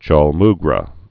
(chôl-mgrə)